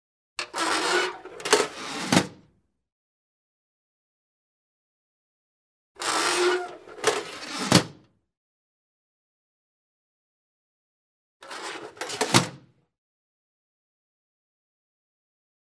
household
Metal Theatre Film Can Close Lid